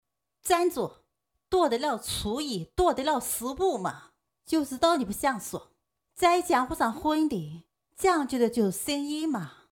女国145_动画_模仿_模仿佟湘玉.mp3